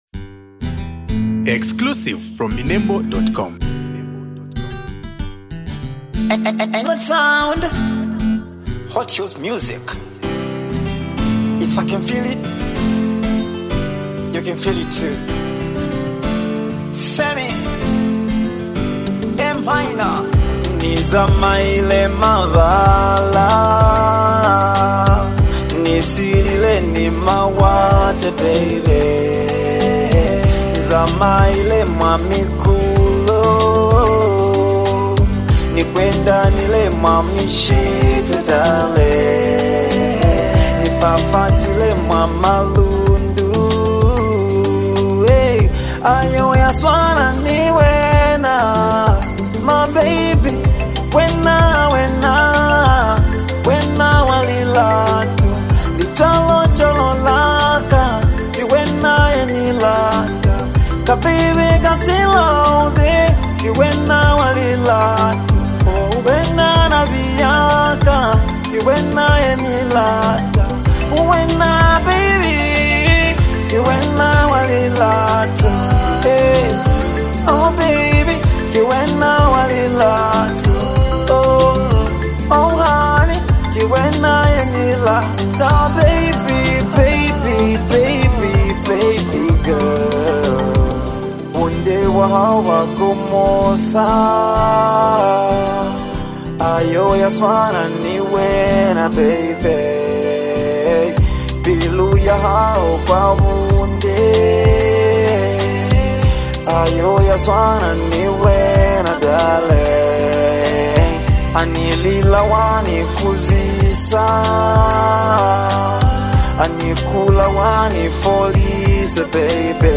R&B
power foreign rhythm
love song